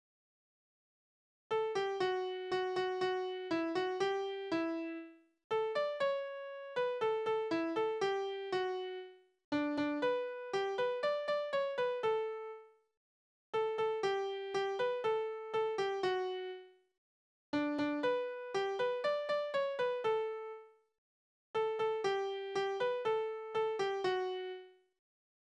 Tonart: D-Dur
Taktart: 4/4
Tonumfang: Oktave
Besetzung: vokal